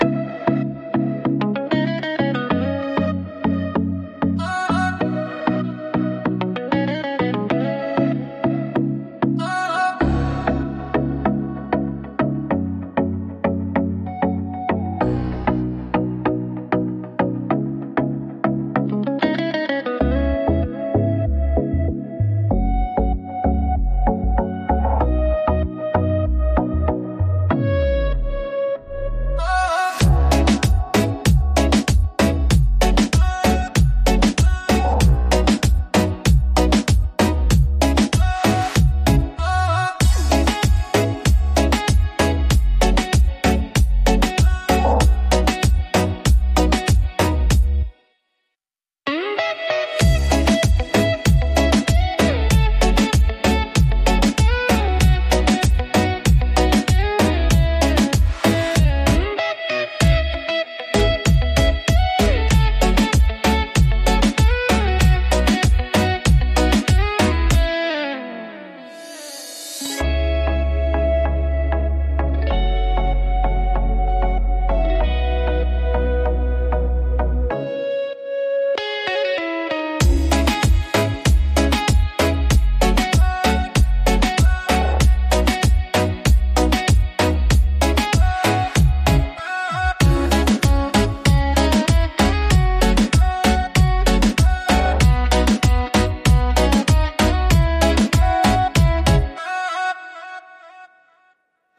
Beat Reggaeton Instrumental
Acapella e Cori Reggaeton Inclusi
F#m